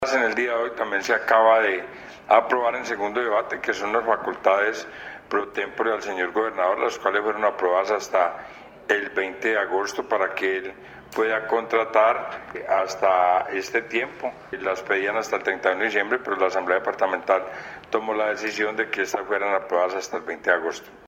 Audio de: entrevista al diputado Cesar Londoño Villegas
Diputado-Cesar-Londono-Villegas.mp3